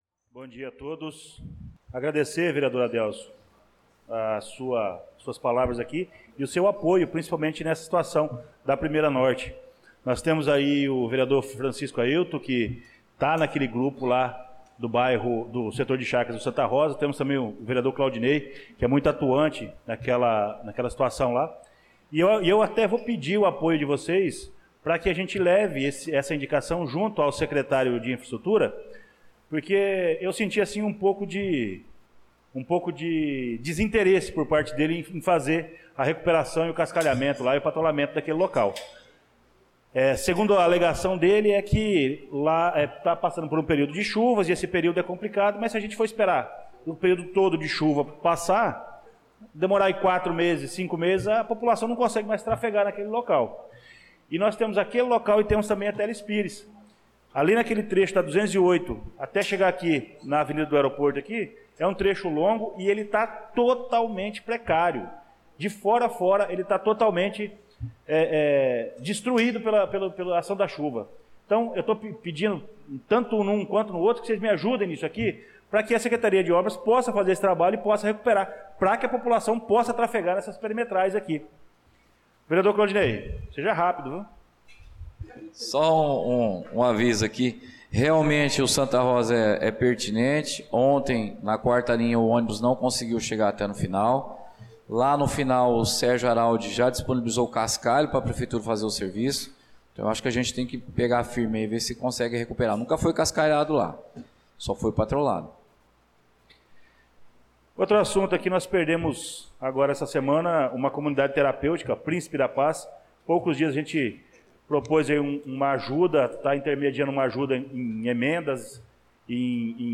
Pronunciamento do vereador Luciano Silva na Sessão Ordinária do dia 18/02/2025